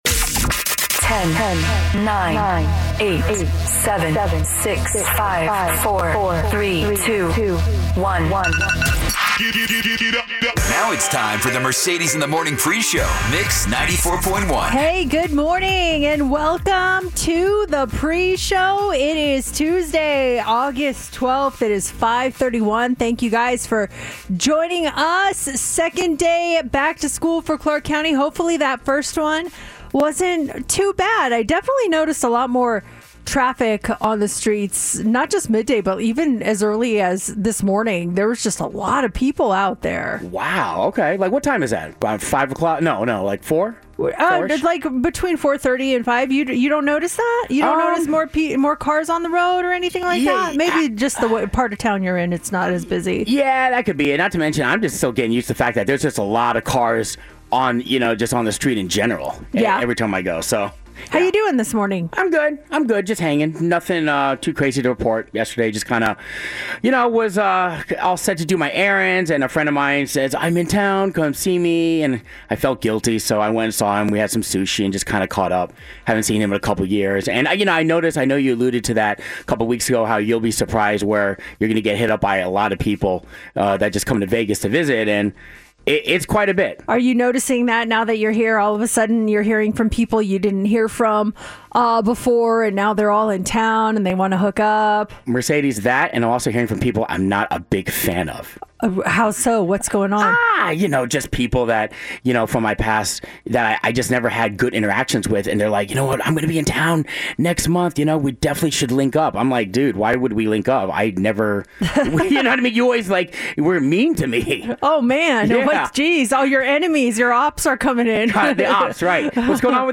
Three local, Las Vegas friends discuss life, current events, and everything else that pops into their heads.